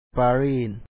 paalìi Paris